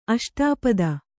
♪ aṣṭāpada